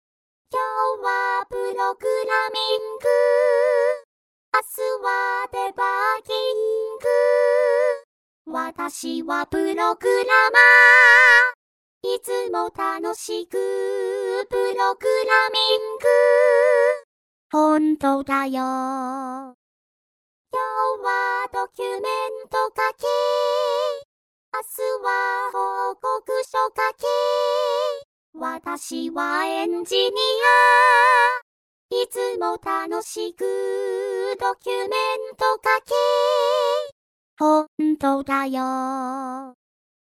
2部合唱